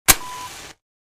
Striking a match sound effect .wav #2
Description: The sound of striking a match
Properties: 48.000 kHz 16-bit Stereo
A beep sound is embedded in the audio preview file but it is not present in the high resolution downloadable wav file.
Keywords: match, matches, striking a match, matchbox, fire, flame, light, lighter
matches-preview-2.mp3